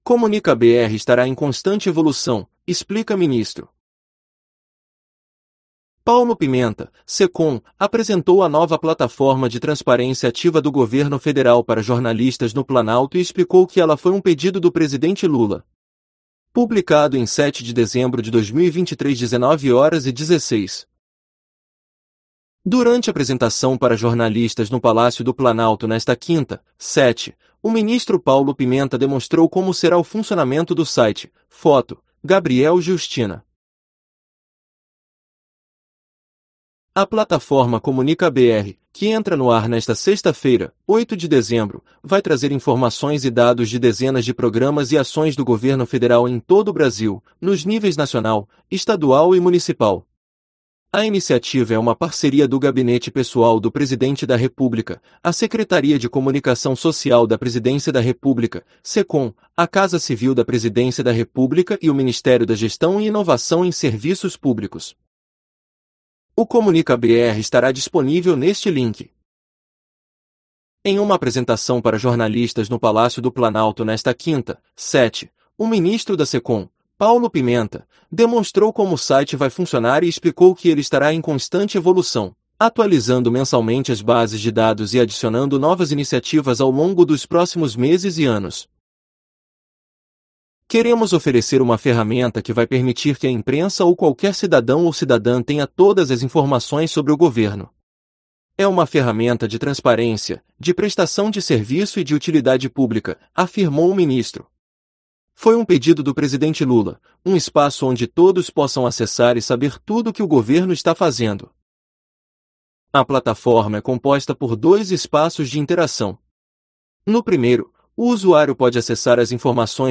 Paulo Pimenta (SECOM) apresentou a nova plataforma de transparência ativa do Governo Federal para jornalistas no Planalto e explicou que ela foi um pedido do presidente Lula